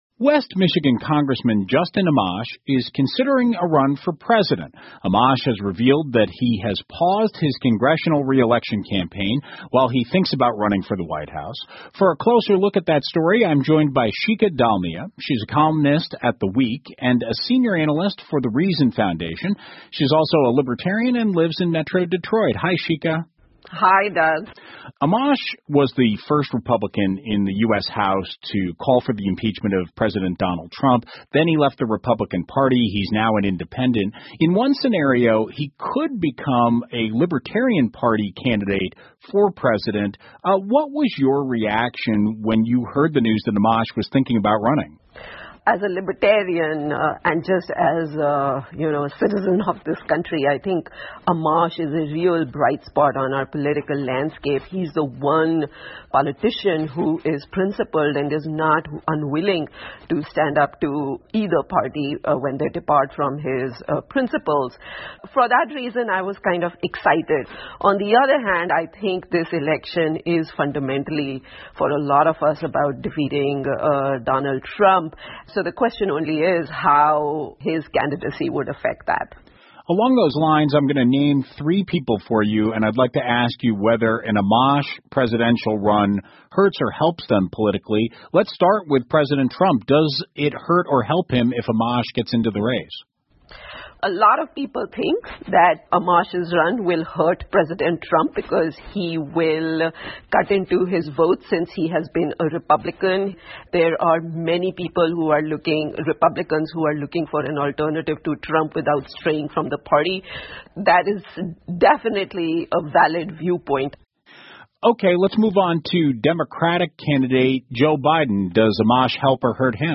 密歇根新闻广播 阿玛什竞选总统可能会伤害到两个团队 听力文件下载—在线英语听力室